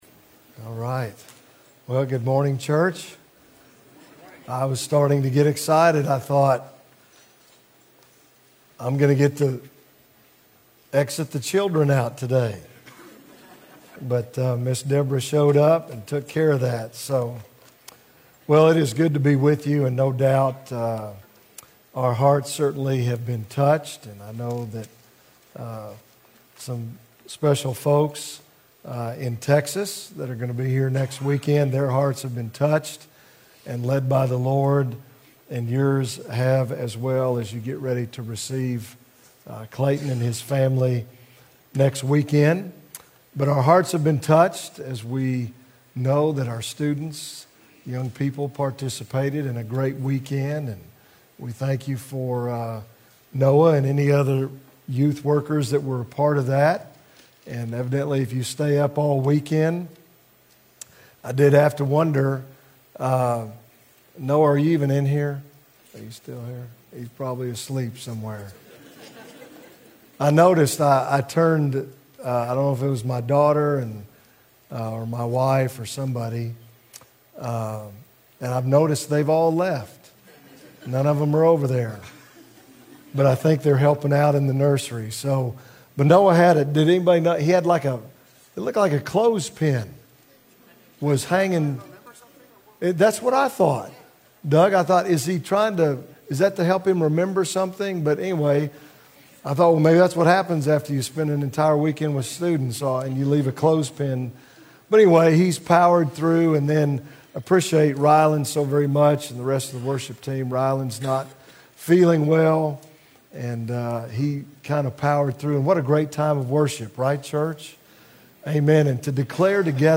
A message from the series "Functional Faith."